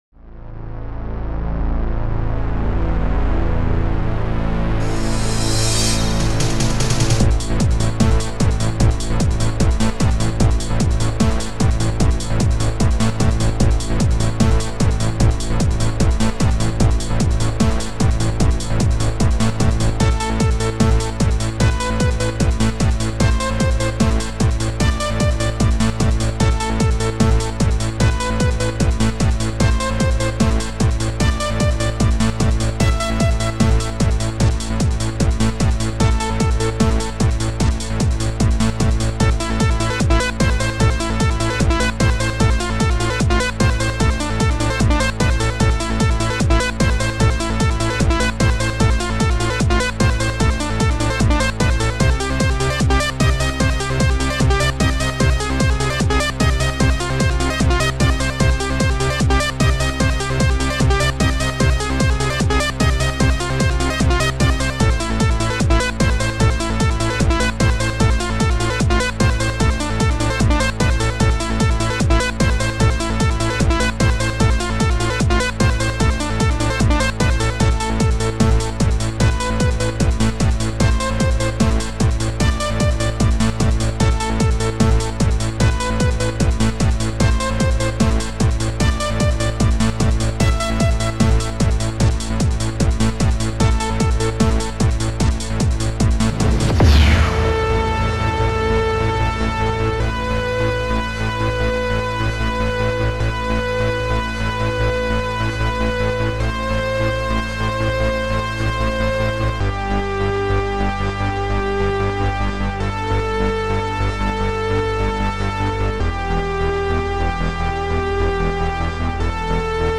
OctaMED Module